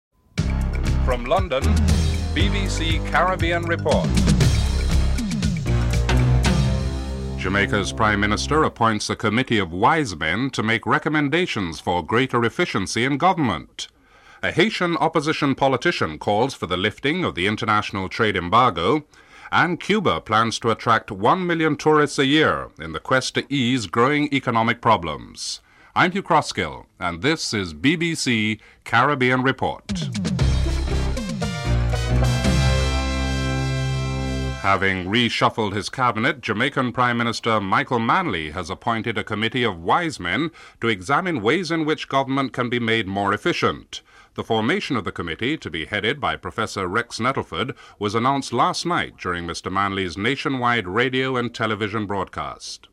1. Headline news (00:00-00:33)
3. Interview with Jamaican Prime Minister, Michael Manley (00:53-01:42)